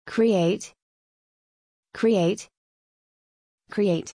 発音記号：/kriˈeɪt/